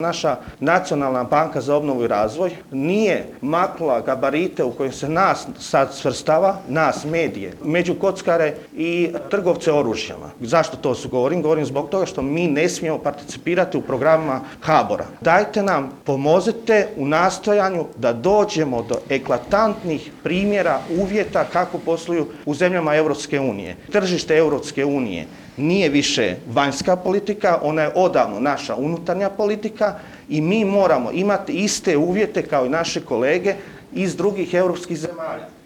ZAGREB - U organizaciji AMM GLOBAL INSTITUTA održan je prvi CROATIA MEDIA CONGRESS na temu izmjena Zakona o elektroničkim medijima.